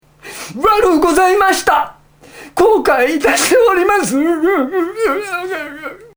楳図かずおが、己の作品のワンシーンに自らの声で命を吹き込むという企画、「UMEZZ VOICE!」第三弾がついに公開！！